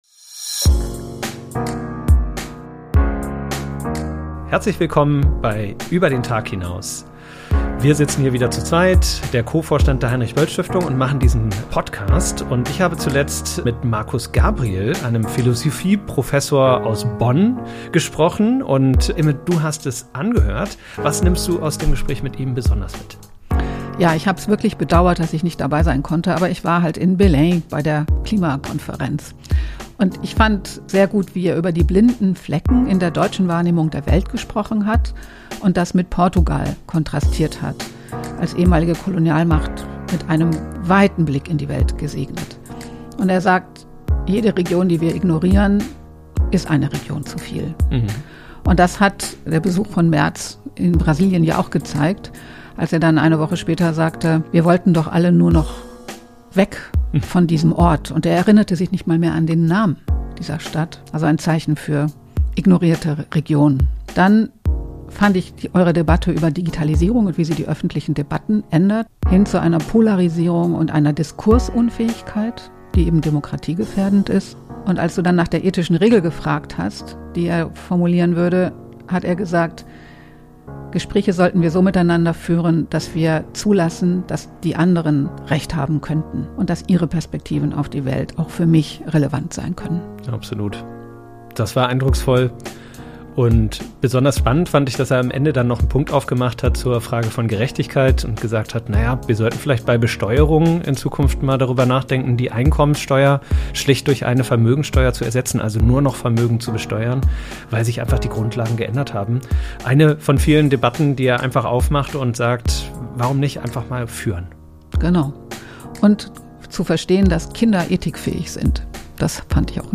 Mit Philosoph Markus Gabriel sprechen wir darüber, warum Moral kein Luxus, sondern die Bedingung unserer Freiheit ist – und welche Rolle Moral gerade in polarisierten Gesellschaften spielen muss.